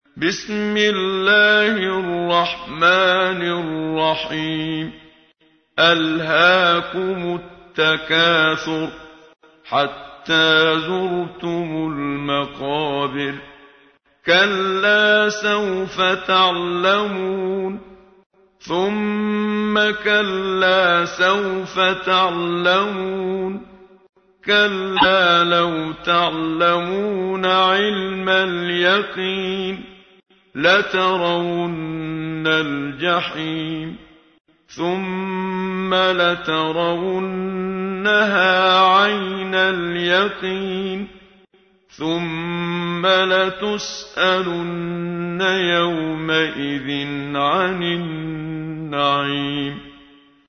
تحميل : 102. سورة التكاثر / القارئ محمد صديق المنشاوي / القرآن الكريم / موقع يا حسين